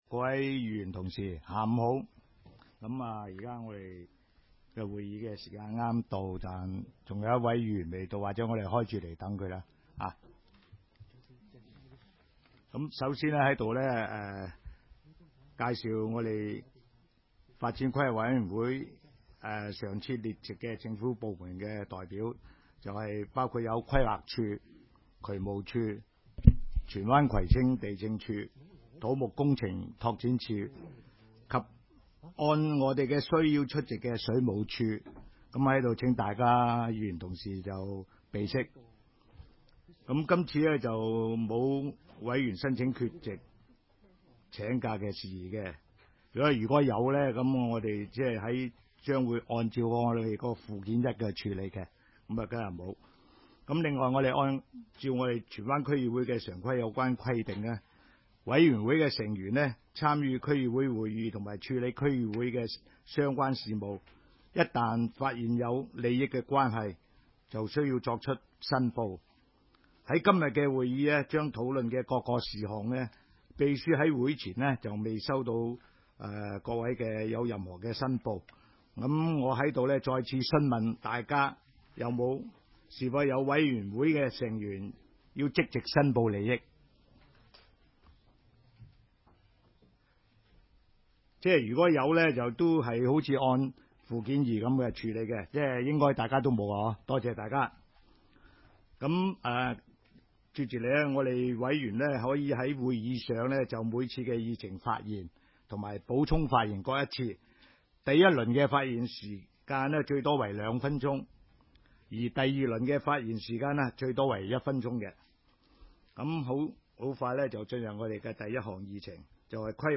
会议的录音记录
荃湾民政事务处会议厅